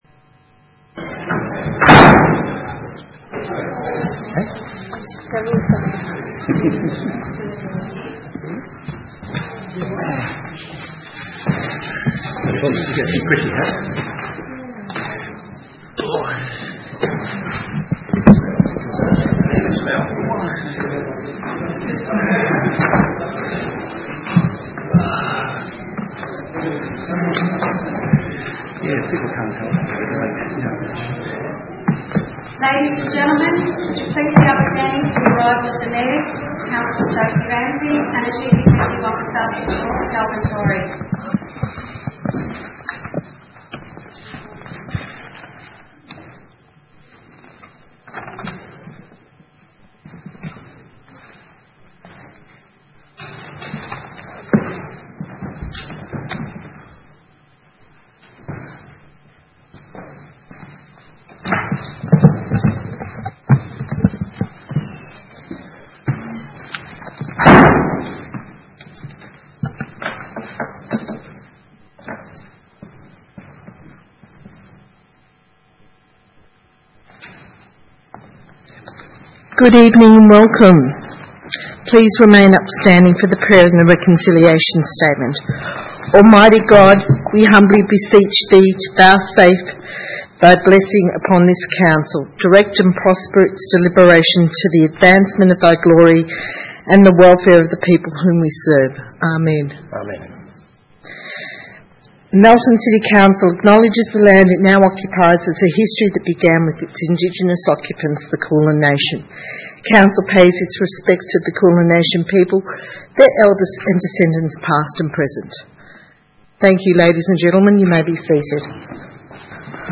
12 December 2016 - Ordinary Council Meeting